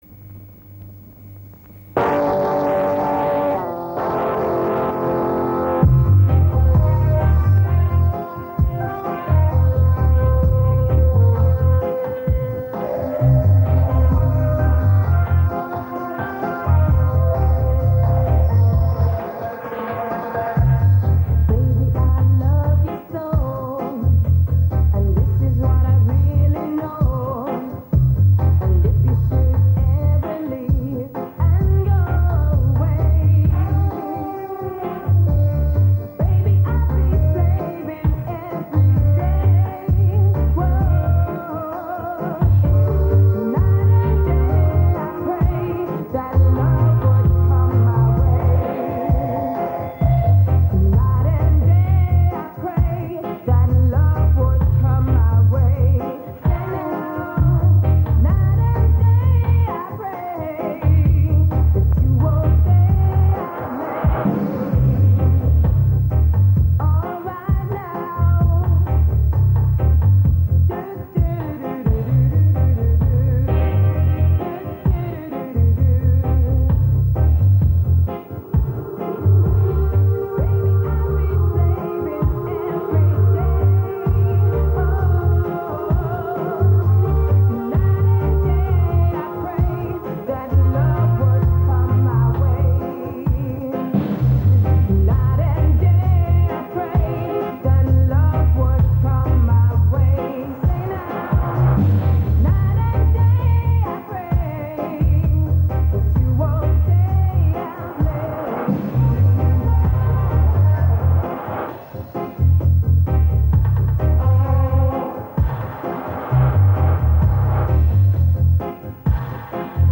Please post only reggae discussions here
im looking for one with a female singer i believe.